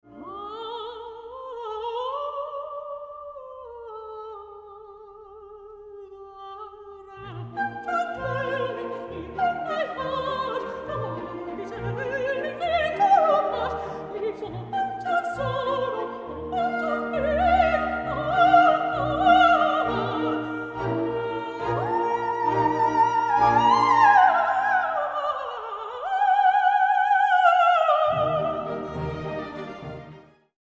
Arias from British Operas